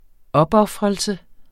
Udtale [ ˈʌbˌʌfʁʌlsə ]